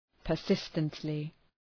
Προφορά
{pər’sıstəntlı}